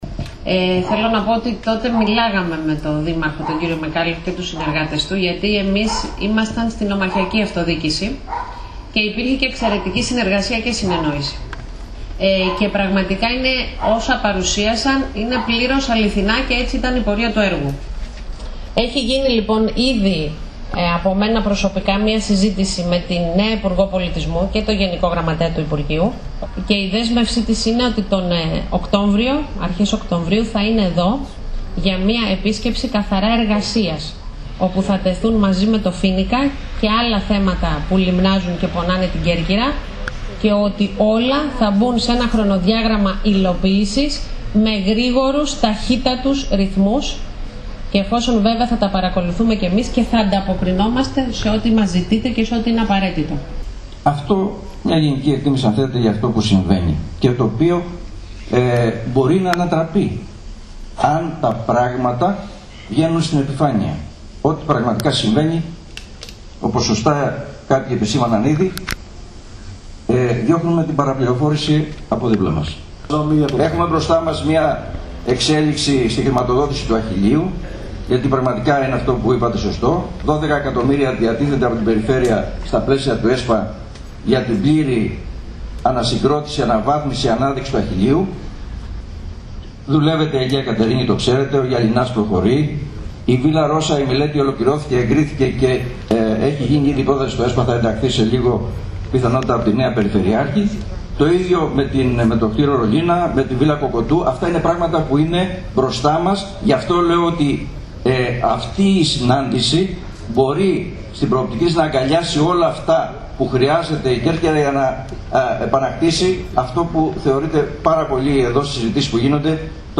Την αναγκαιότητα της άμεσης και ουσιαστικής διάσωσης και ανάπλασης του ιστορικού θεάτρου Φοίνικας στην πόλη της Κέρκυρας, τόνισαν οι εκπρόσωποι των φορέων του νησιού σε εκδήλωση που διοργανώθηκε με τη συμμετοχή πλήθος κόσμου.